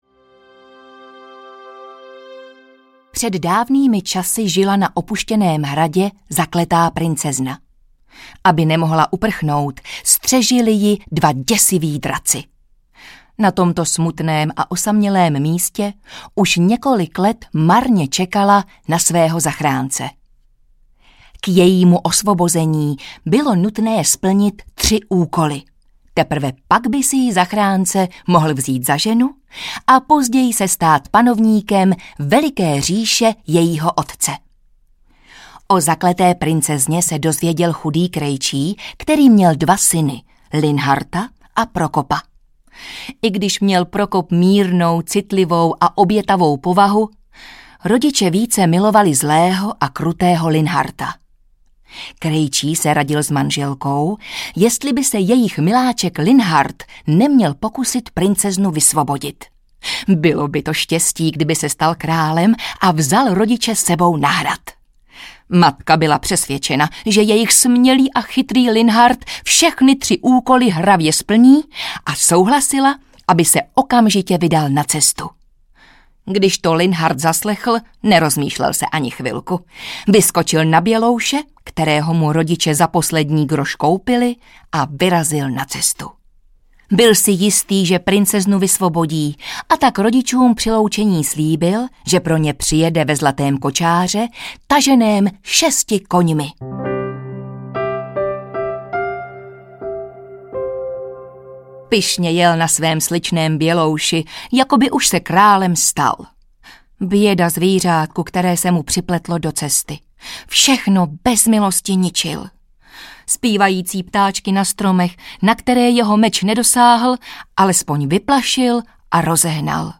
Pohádky audiokniha
Ukázka z knihy